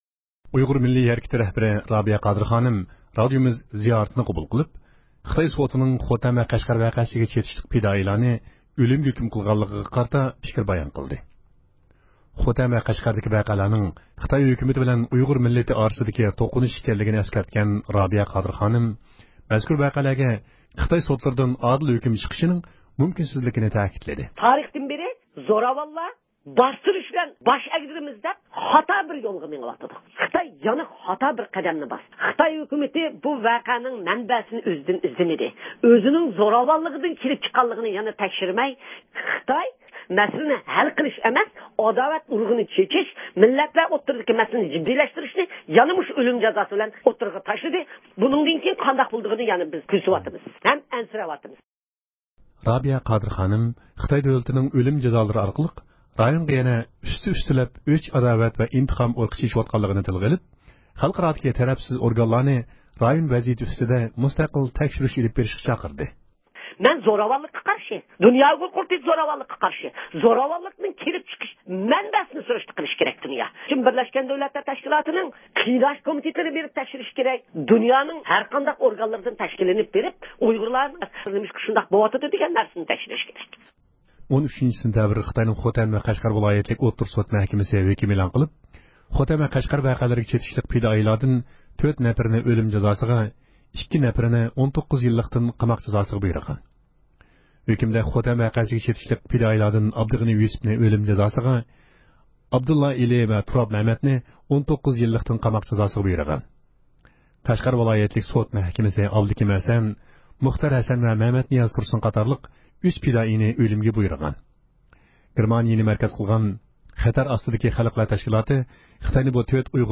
ھەپتىلىك خەۋەرلەر (10-سېنتەبىردىن 16-سېنتەبىرگىچە) – ئۇيغۇر مىللى ھەركىتى
ئۇيغۇر مىللىي ھەرىكىتىنىڭ رەھبىرى رابىيە قادىر خانىم، رادىئومىز زىيارىتىنى قوبۇل قىلىپ، خىتاي سوتىنىڭ خوتەن ۋە قەشقەر ۋەقەسىگە چېتىشلىق پىدائىيلارنى ئۆلۈمگە ھۆكۈم قىلغانلىقىغا قارىتا پىكىر بايان قىلدى.